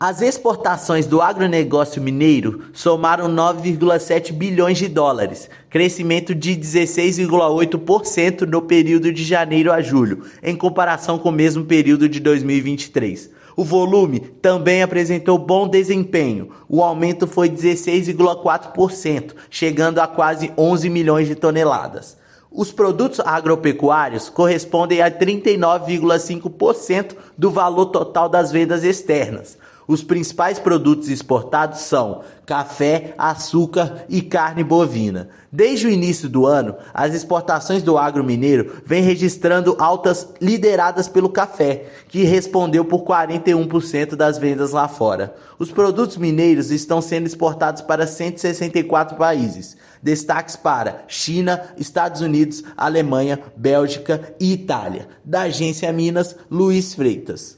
Valorização do café continua puxando o bom desempenho do setor. O valor representa 39,5% do total das vendas externas do estado. Ouça matéria de rádio.